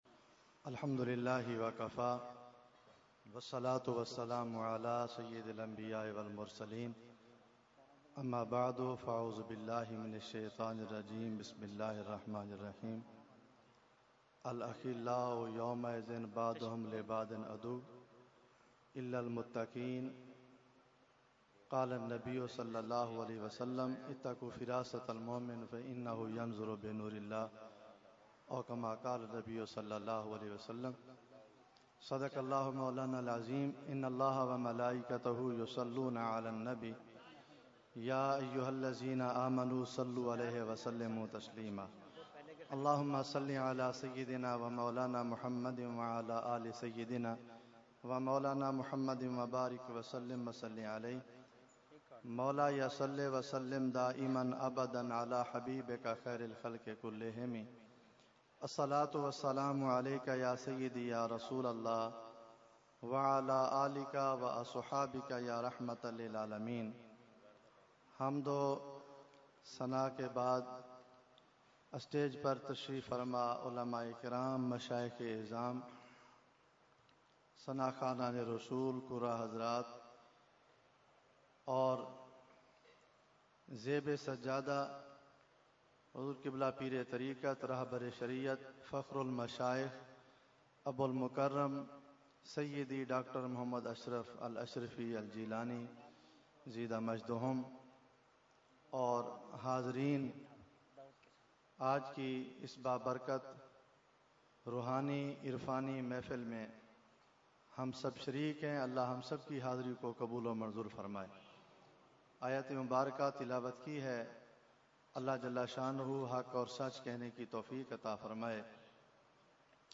Mediaa: Urs Makhdoome Samnani 2016
Category : Speech | Language : UrduEvent : Urs Makhdoome Samnani 2016